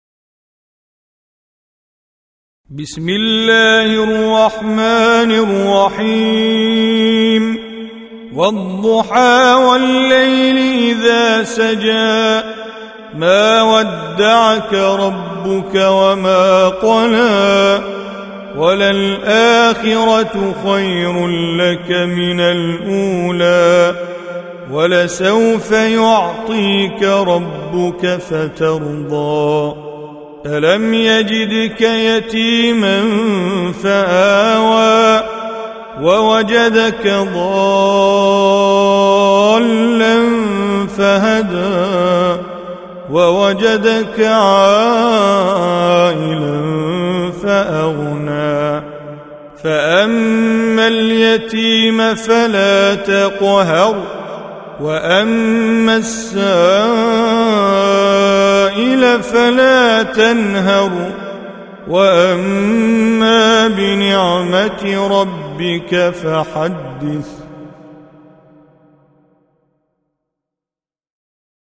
93. Surah Ad-Duha سورة الضحى Audio Quran Tajweed Recitation
Surah Sequence تتابع السورة Download Surah حمّل السورة Reciting Mujawwadah Audio for 93. Surah Ad-Duha سورة الضحى N.B *Surah Includes Al-Basmalah Reciters Sequents تتابع التلاوات Reciters Repeats تكرار التلاوات